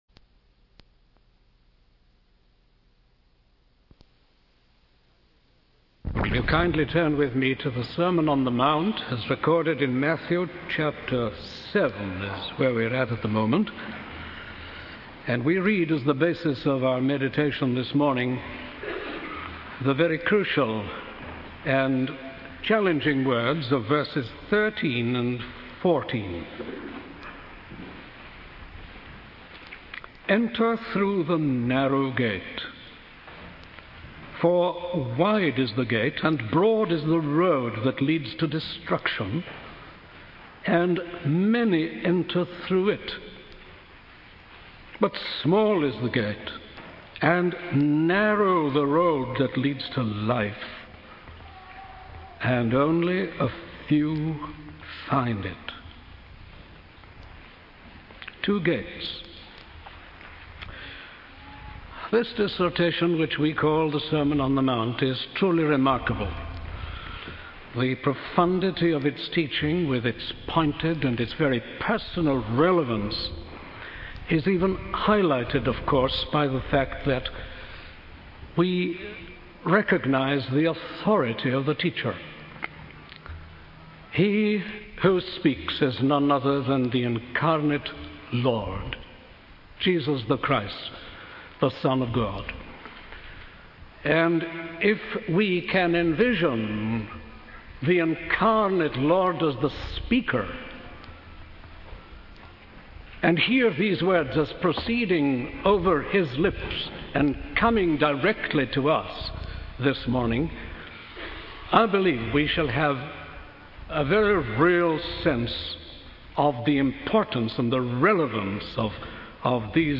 In this sermon on the Sermon on the Mount, the speaker emphasizes the importance and relevance of Jesus' teachings.